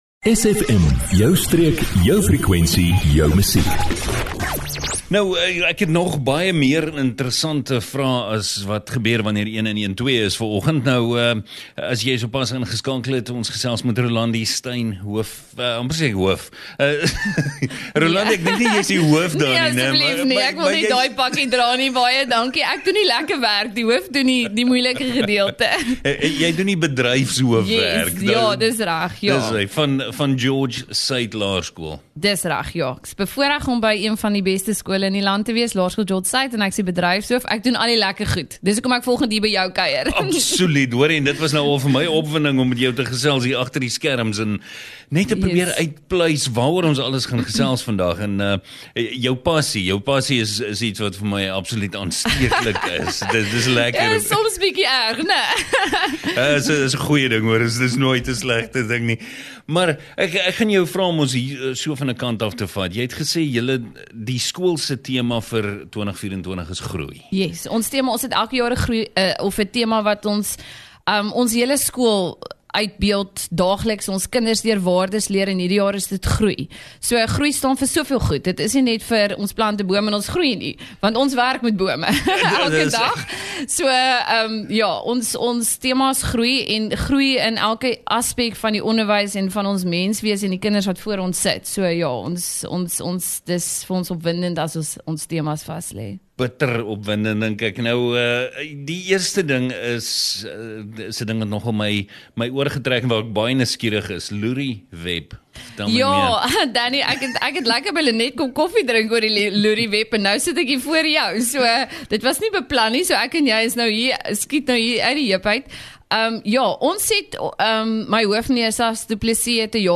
22 Jul Laerskool George-Suid - Jaar Tema - Groei - onderhoud 22 Julie 2024